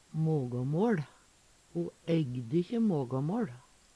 mågåmåL - Numedalsmål (en-US)
See also stappe i se (Veggli) Hør på dette ordet Ordklasse: Substantiv inkjekjønn Kategori: Kropp, helse, slekt (mennesket) Attende til søk